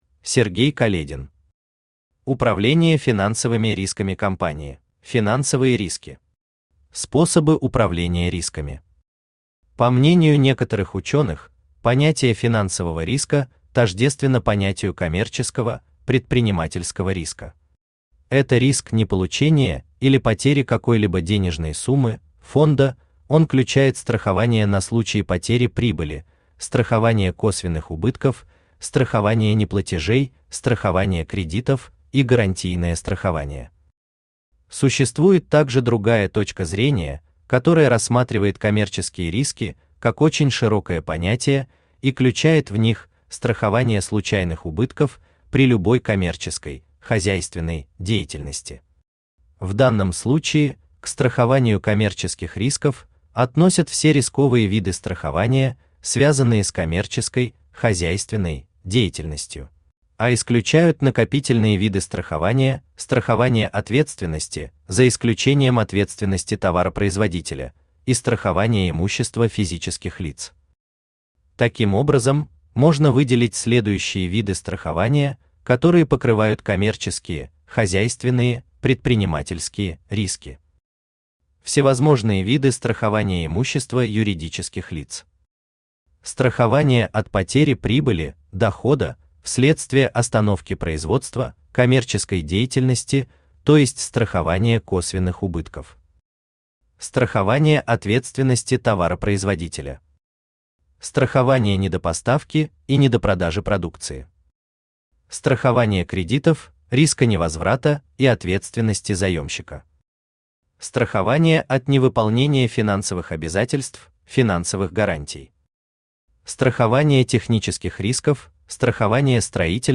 Aудиокнига Управление финансовыми рисками компании Автор Сергей Каледин Читает аудиокнигу Авточтец ЛитРес. Прослушать и бесплатно скачать фрагмент аудиокниги